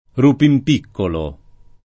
Rupinpiccolo [ rupimp & kkolo ]